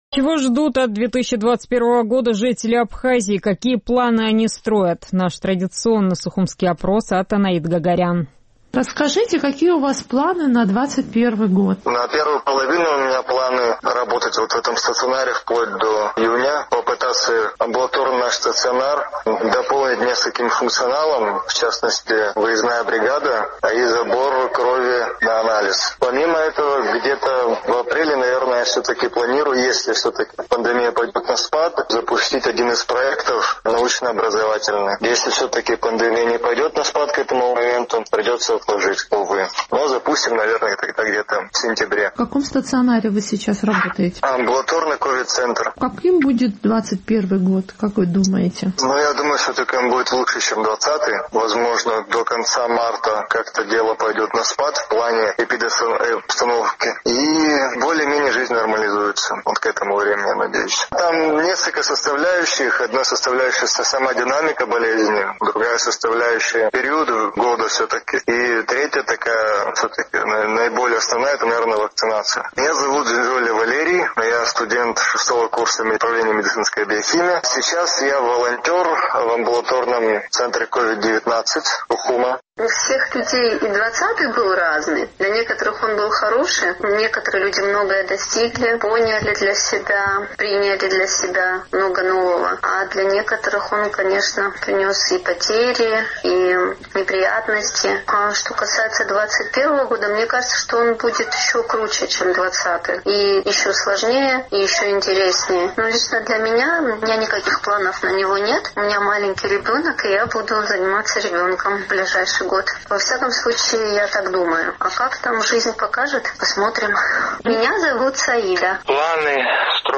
Чего ждут от 2021 года жители Абхазии, какие планы строят? Наш традиционный сухумский опрос.